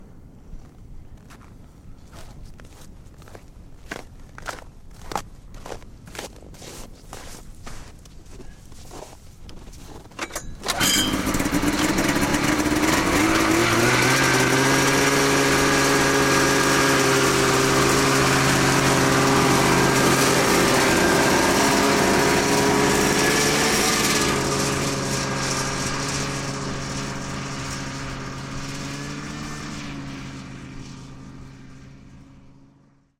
努纳维克 " 雪地摩托的脚步声 靴子坚硬清脆的雪地走到雪地摩托前，启动并拉开距离
描述：雪地摩托的脚步靴坚硬脆脆的雪走到雪地摩托车，开始和拉远
Tag: 脚步 雪地车 启动 走路 靴子 脆脆的